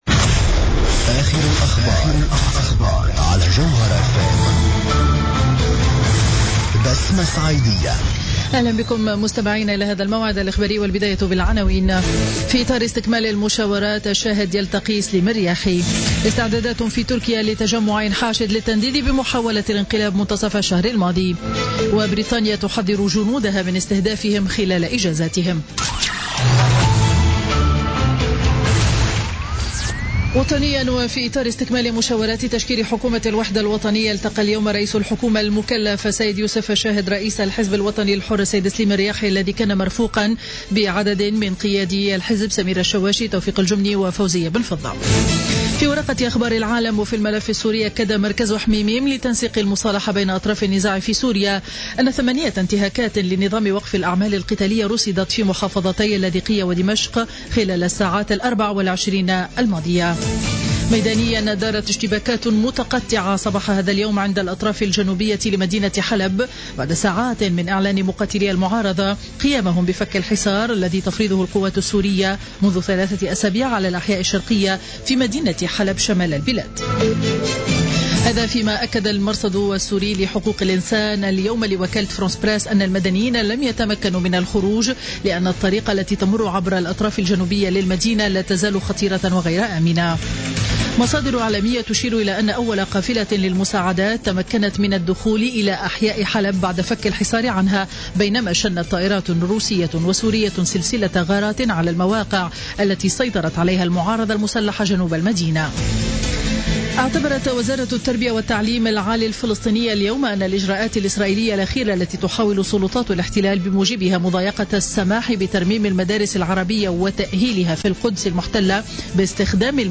نشرة أخبار منتصف النهار ليوم الأحد 7 أوت 2016